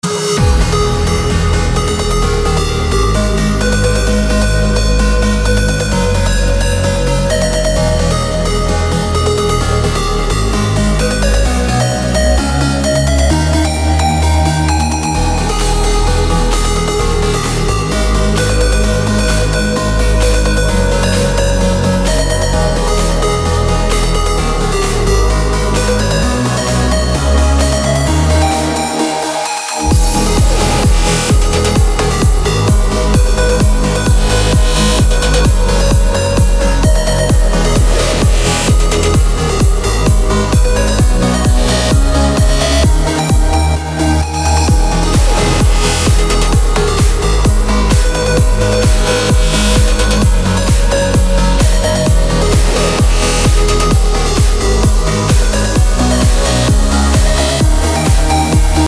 • Качество: 256, Stereo
красивые
Electronic
без слов
club
Trance
Progressive
Жанр: EDM Trance/Progressive